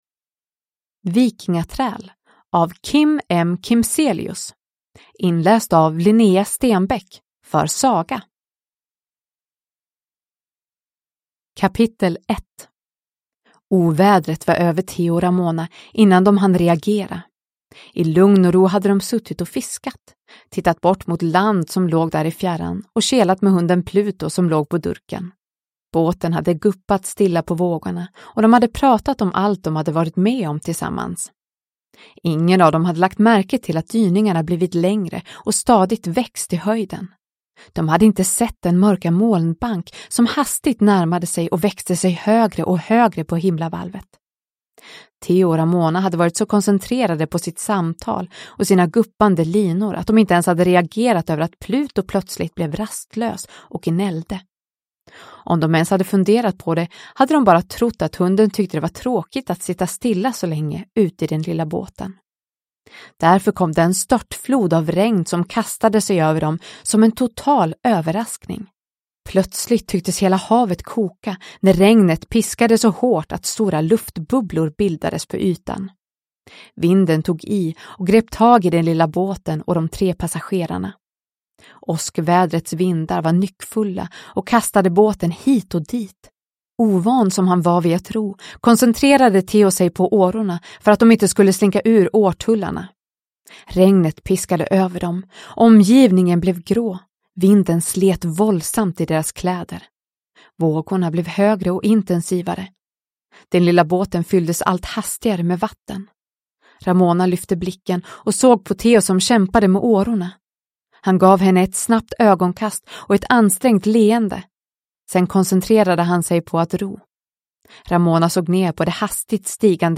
Vikingaträl / Ljudbok